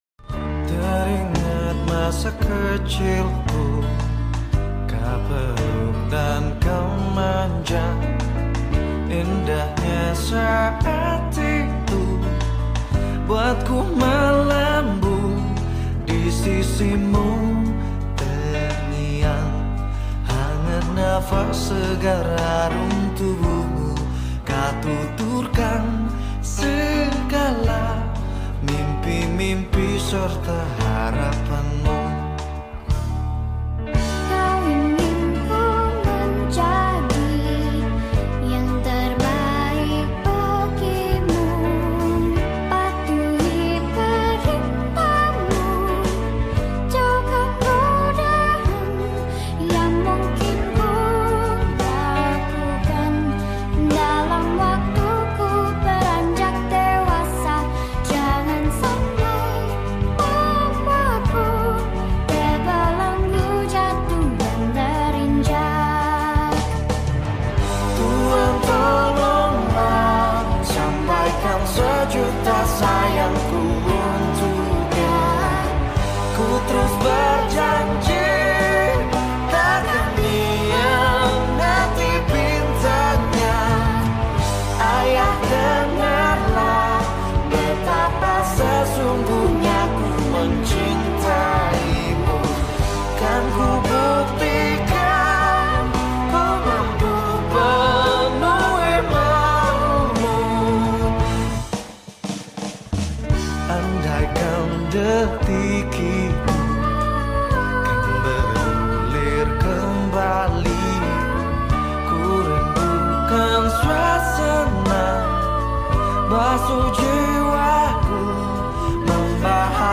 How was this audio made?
Pejuang Keluarga seorang ojek online tengah tertidur lelap di jalan yang bising siang hari di jalan Wahid Hasyim Jakarta Pusat (29/7/2025).